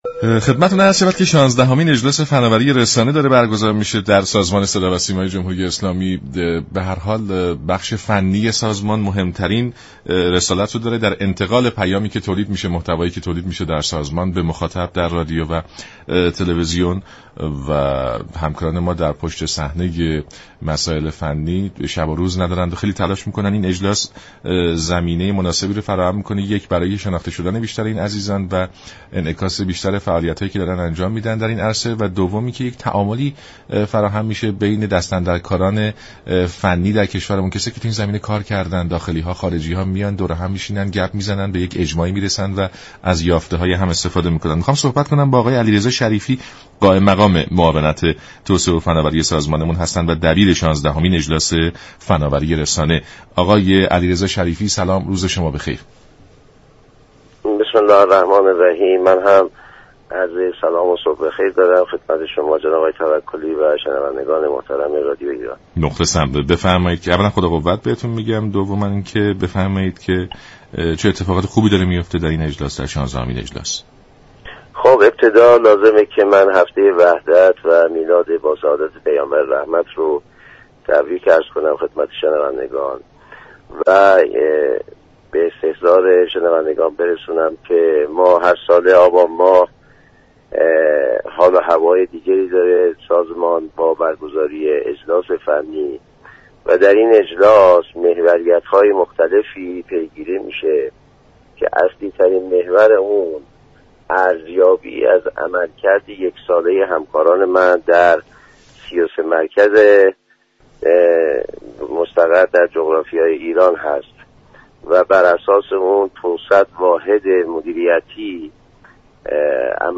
در گفت و گو با برنامه «سلام صبح بخیر»